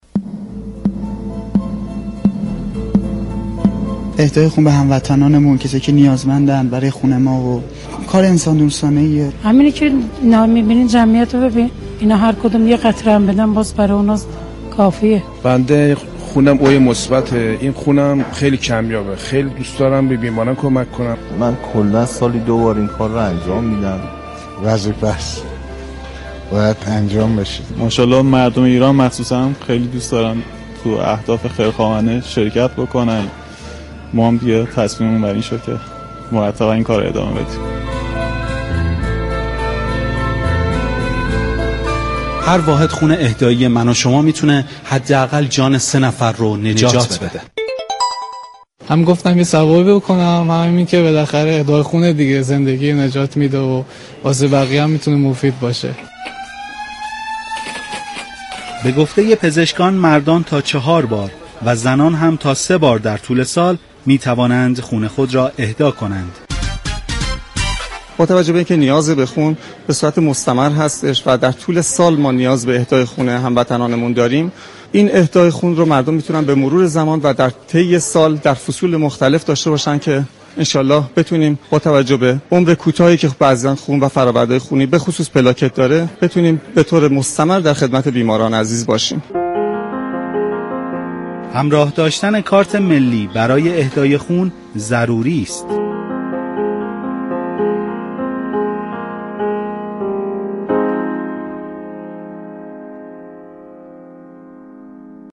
گزارش برنامه خانه و خانواده مربوط به روز اهدا خون است به اتفاق بشنویم